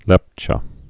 (lĕpchə)